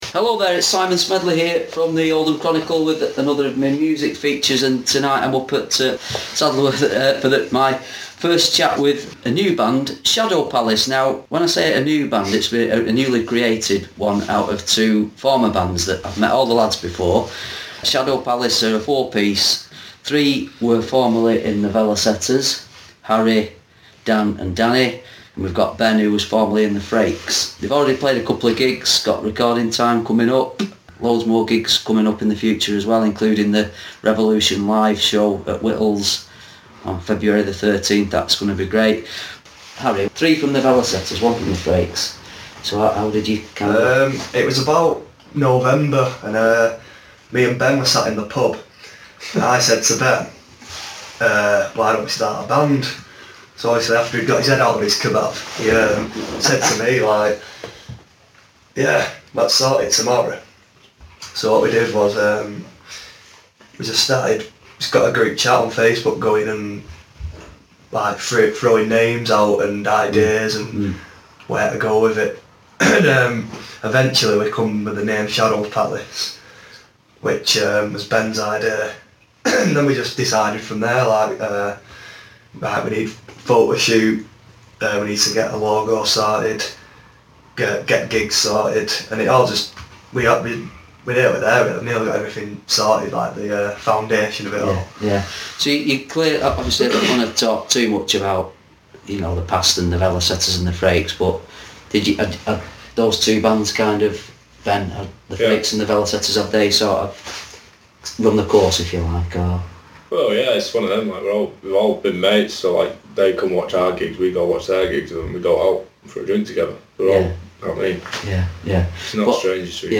Shadow Palace in conversation